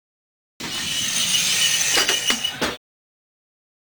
whine_clank.ogg